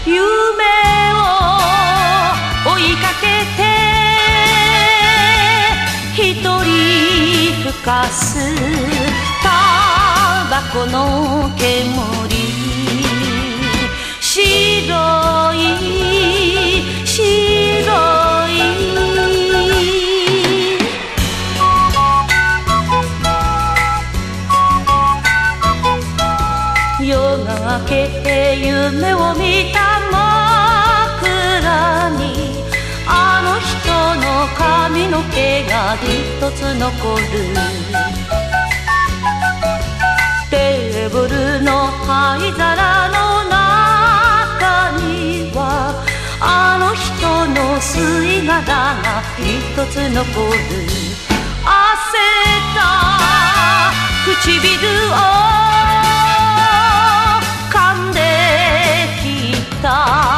EASY LISTENING / VOCAL / JAPANESE
カタコト日本語歌謡！
ウットリするようなヴォーカルでしっとりと歌い上げるカタコト日本語歌謡！